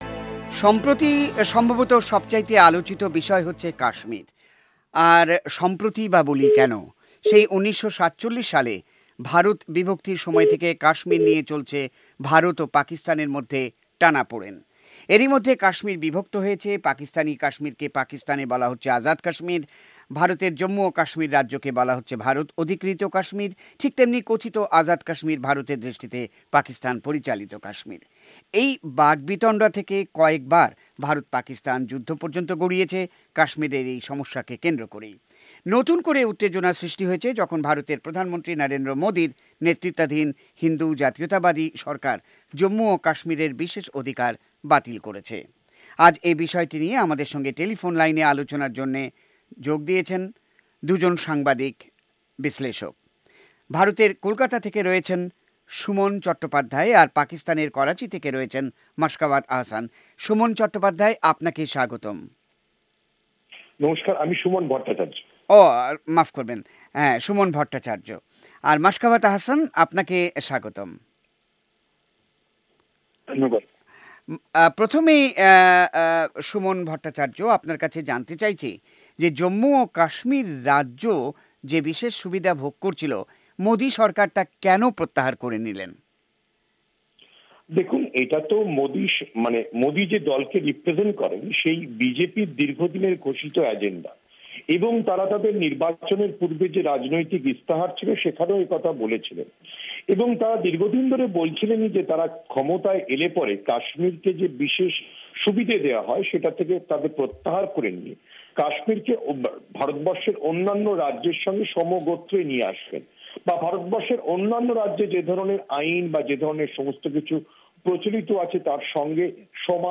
কাশ্মির নিয়ে ভারত-পাকিস্তান বাক-বিতন্ডা সম্পর্কে আলোচনা